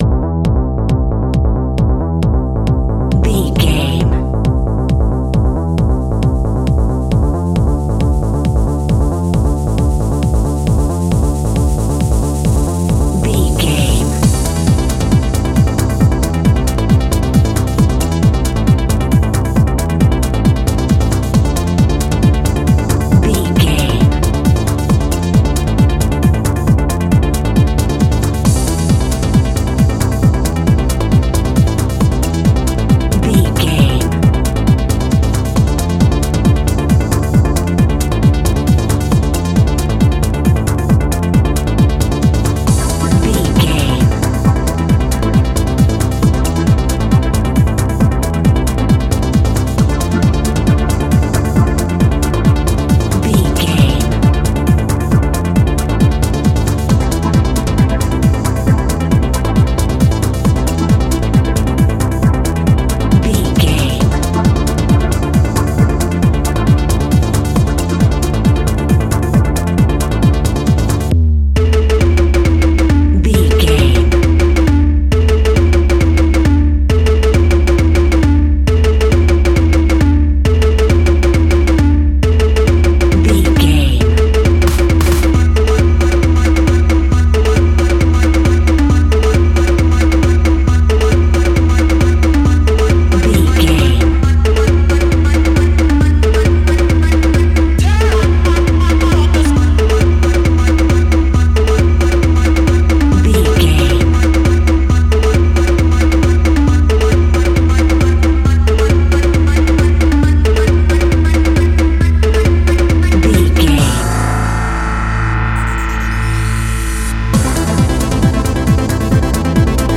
Aeolian/Minor
Fast
energetic
uplifting
hypnotic
drum machine
synthesiser
acid trance
uptempo
synth leads
synth bass